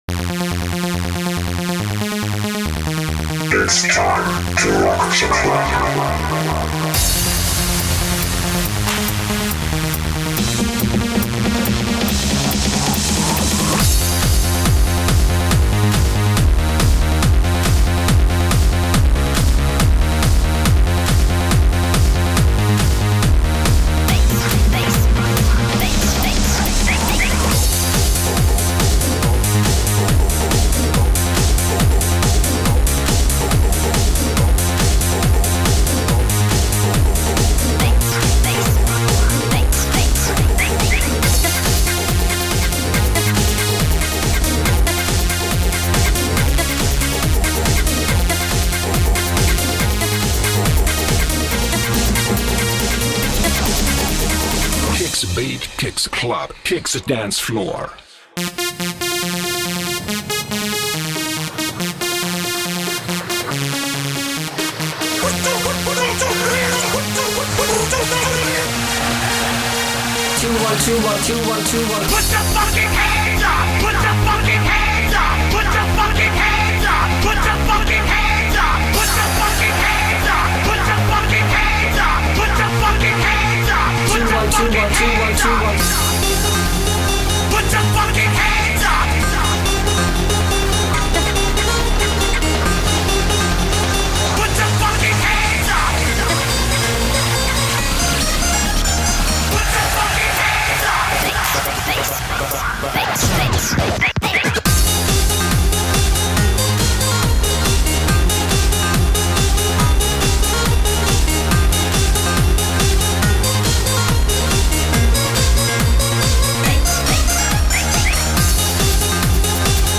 EuroDance _ MEGAMIX